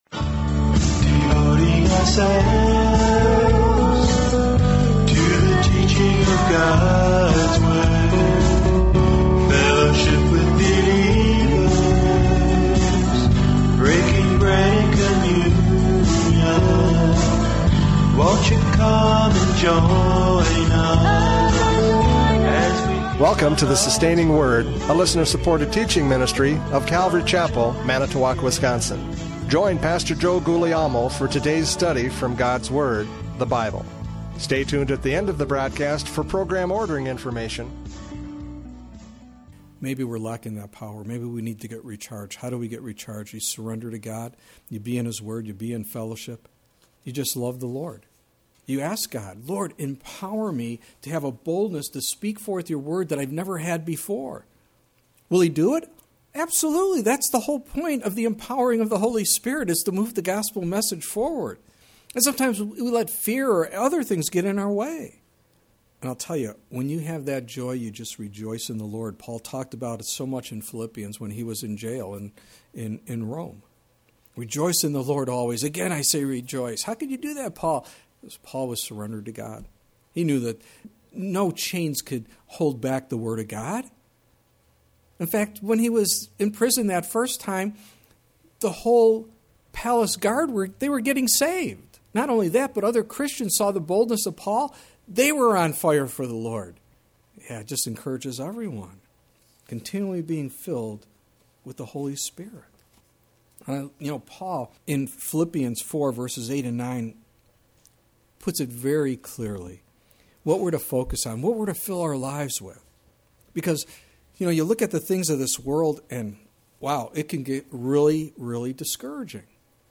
Judges 5:3-5 Service Type: Radio Programs « Judges 5:3-5 Remember God!